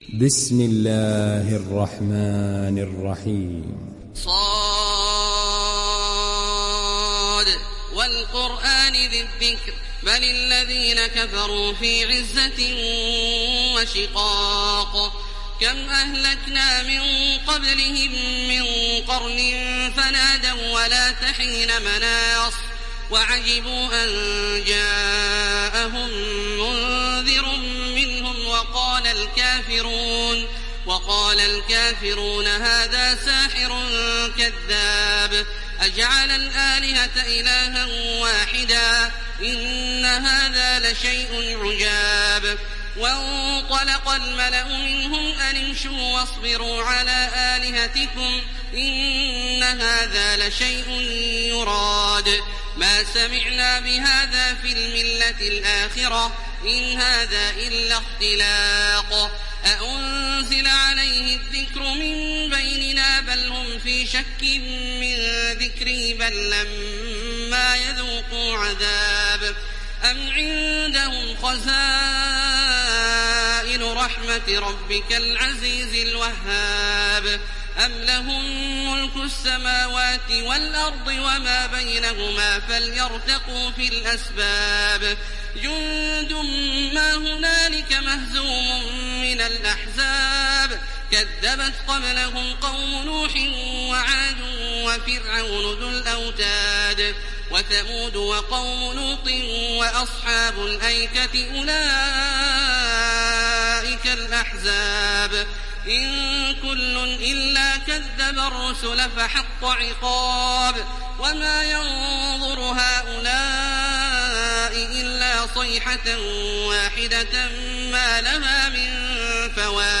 دانلود سوره ص mp3 تراويح الحرم المكي 1430 روایت حفص از عاصم, قرآن را دانلود کنید و گوش کن mp3 ، لینک مستقیم کامل
دانلود سوره ص تراويح الحرم المكي 1430